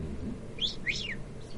Descarga de Sonidos mp3 Gratis: perico.
descargar sonido mp3 perico
periquito_1.mp3